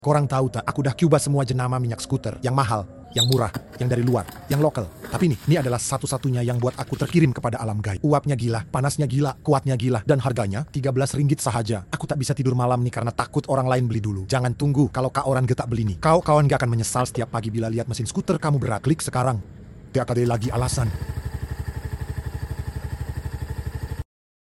Nak engine scooter korang running sound effects free download
Nak engine scooter korang running sedap?try cuba BOLD